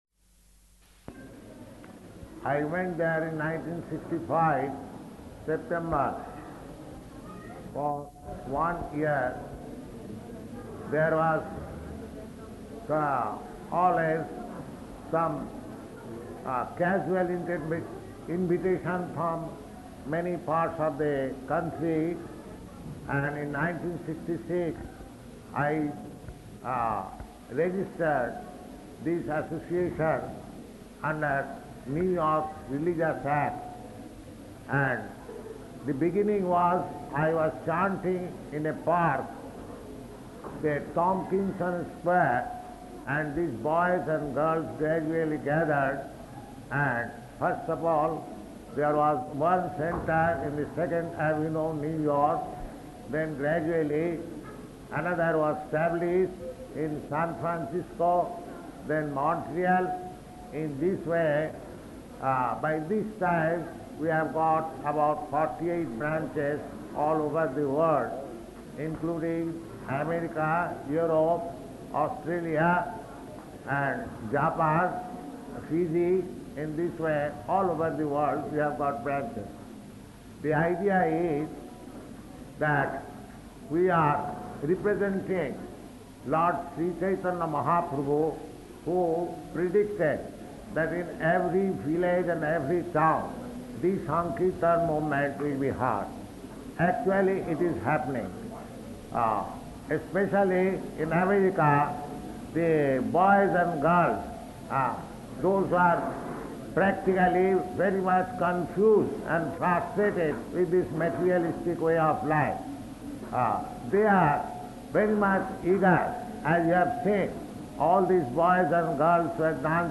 Paṇḍāl Lecture [Partially Recorded]
Location: Allahabad